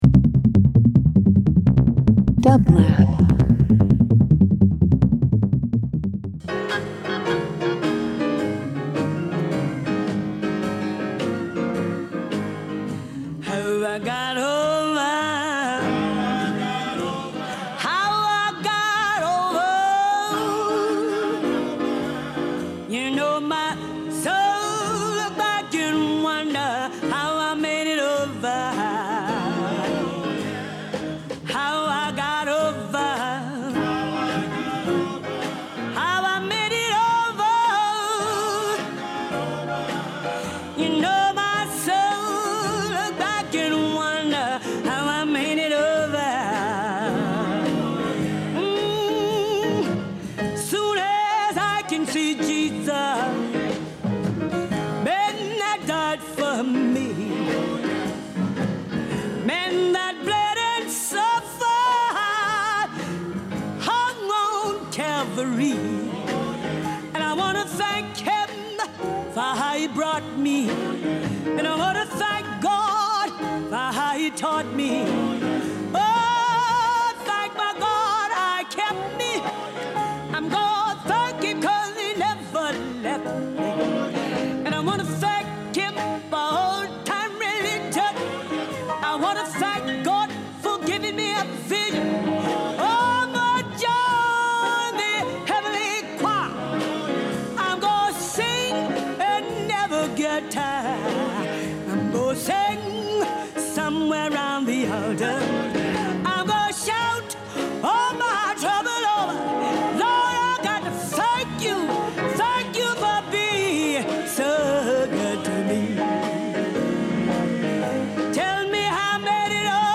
Jazz Rock Soul